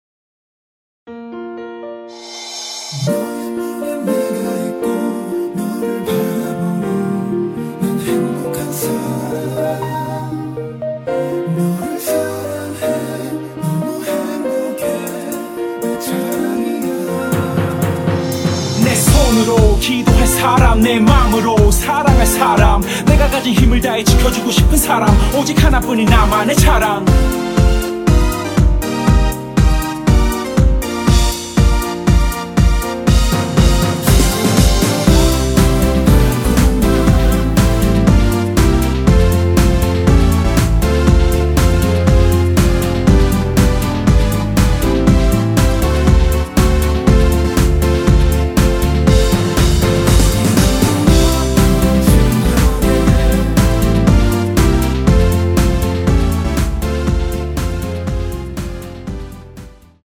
전주 없이 시작 하는곡이라 전주 1마디 만들어 놓았습니다.
(-1) 내린 코러스및 랩 포함된 MR 입니다.(미리듣기 참조)
Bb
◈ 곡명 옆 (-1)은 반음 내림, (+1)은 반음 올림 입니다.
앞부분30초, 뒷부분30초씩 편집해서 올려 드리고 있습니다.